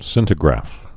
(sĭntĭ-grăf)